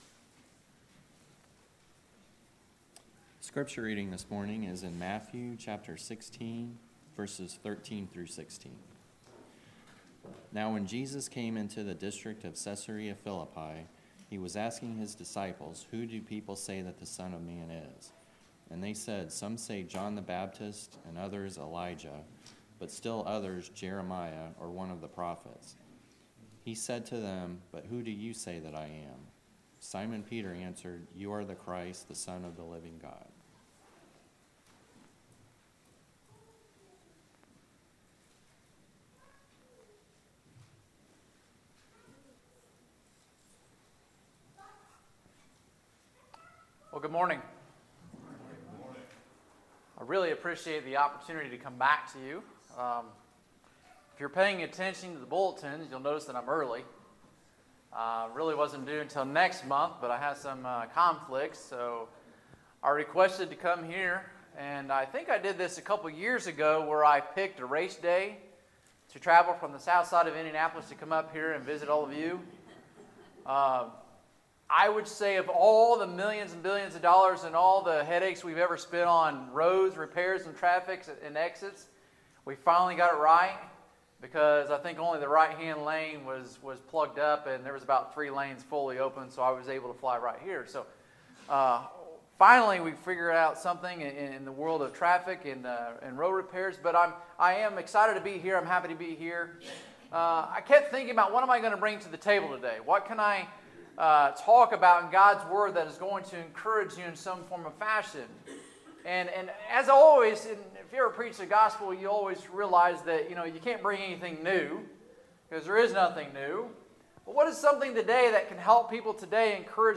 Sermons, May 26, 2019